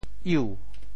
“鲔”字用潮州话怎么说？
鲔（鮪） 部首拼音 部首 鱼 总笔划 14 部外笔划 6 普通话 wěi 潮州发音 潮州 iu2 文 中文解释 鲔 <名> 鲟鱼和鳇鱼的古称 [sturgeon] 鲔鱼,色青黑,头小而尖,似铁兜鍪,口在颔下,其甲可以磨姜,大者不过七八尺。